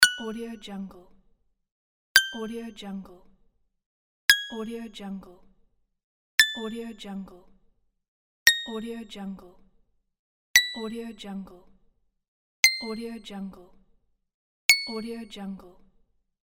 دانلود افکت صوتی مجموعه گرفتن سکه و امتیاز